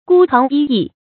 孤行一意 gū xíng yī yì
孤行一意发音